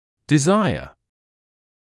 [dɪ’zaɪə][ди’зайэ]желание; желать